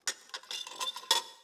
SFX_Plates+Utensil_02.wav